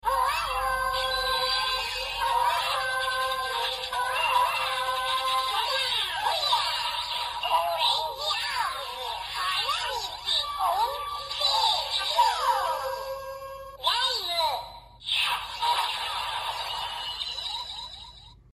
铠武饱藏音效.MP3